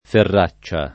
ferraccia [ ferr #©© a ] s. f. (zool.); pl. -ce